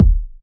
• Solid Urban Steel Kick Drum Sound D# Key 190.wav
Royality free kick single hit tuned to the D# note. Loudest frequency: 117Hz
solid-urban-steel-kick-drum-sound-d-sharp-key-190-wXg.wav